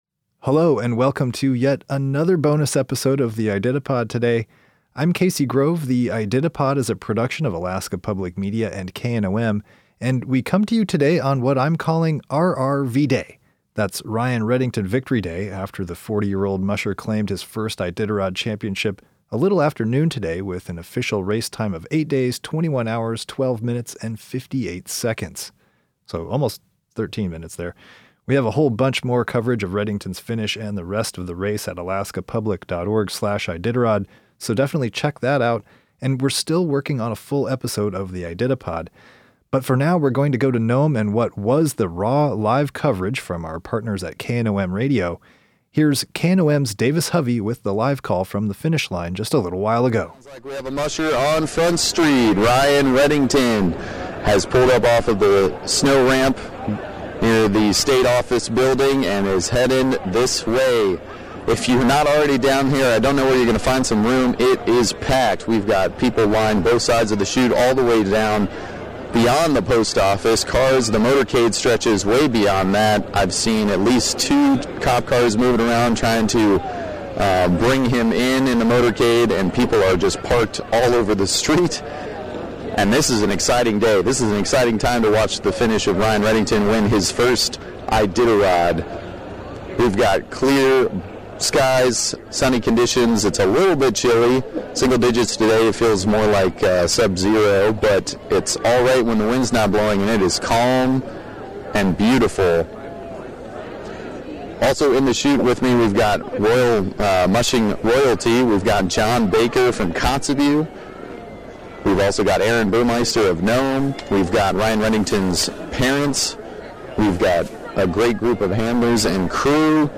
Live call: Ryan Redington wins 2023 Iditarod